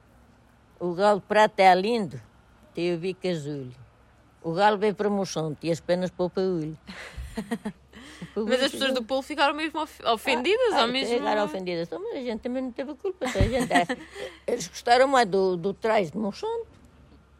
Uma picardia que resistiu ao tempo e ainda se entoa por aí, mas agora sem rivalidades.